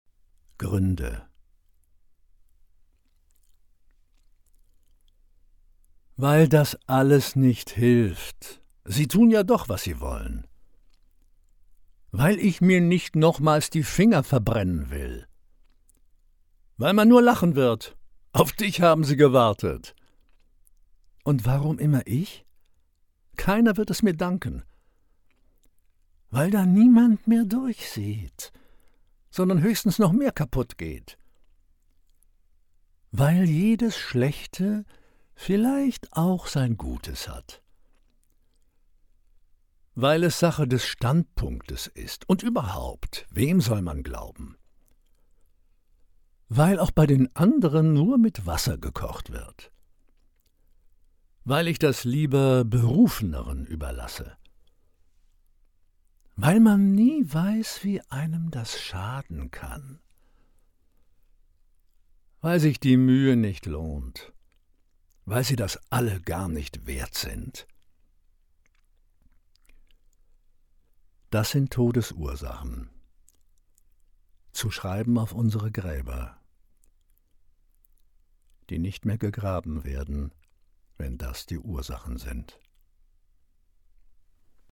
Karsamstag 2025